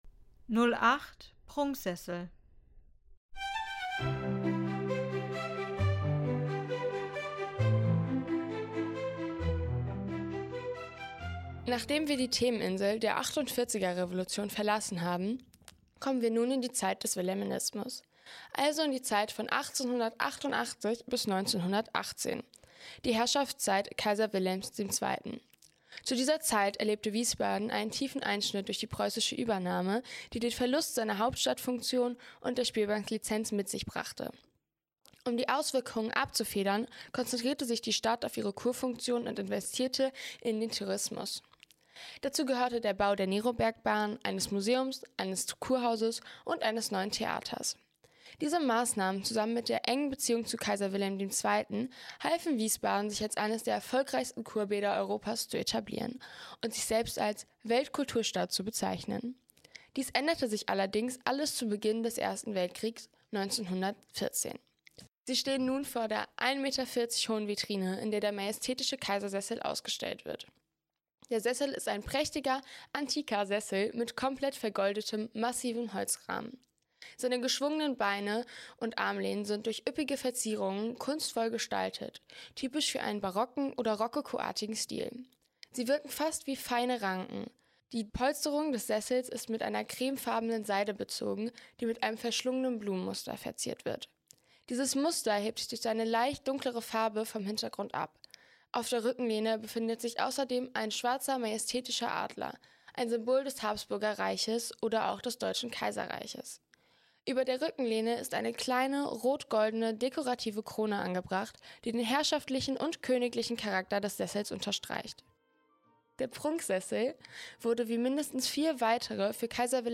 Audioguide - Prunksessel